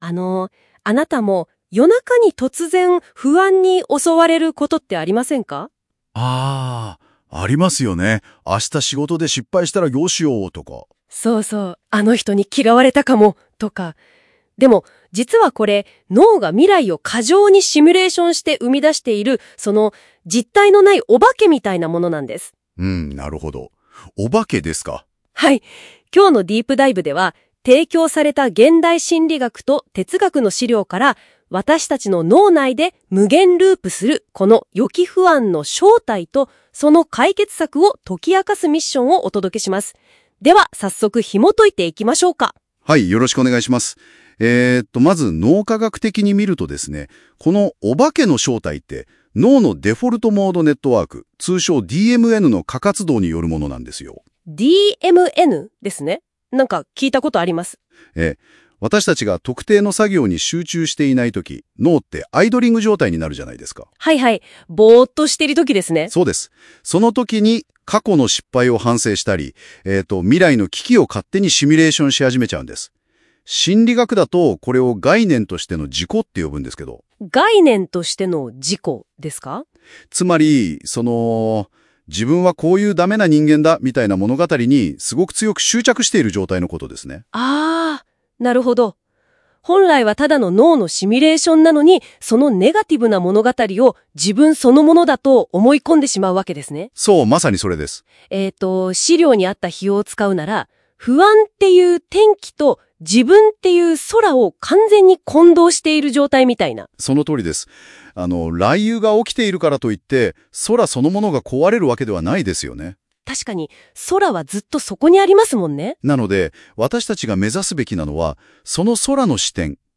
【音声解説】不安ループを止めるメタメタ認知